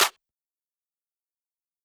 Metro Claps [Flame].wav